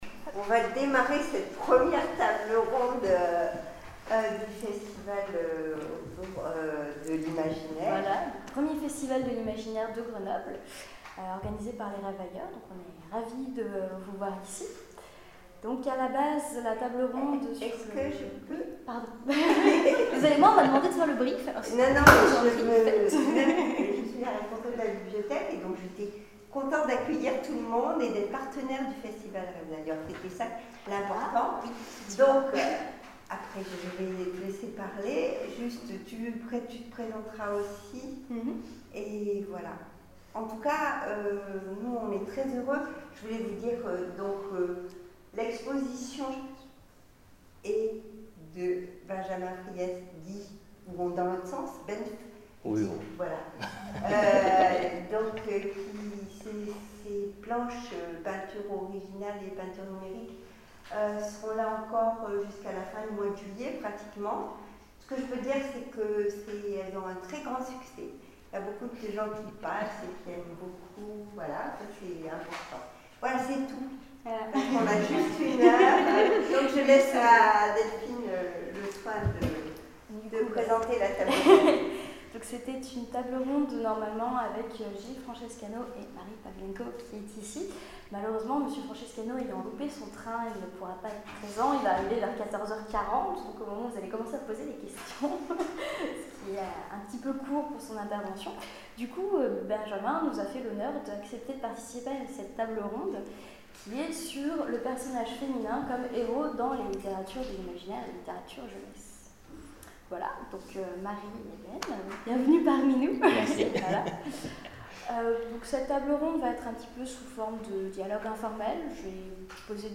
Conférence : Rêves d'Ailleurs, Fig 2012 : Le personnage féminin comme héros de l’Imaginaire en littérature jeunesse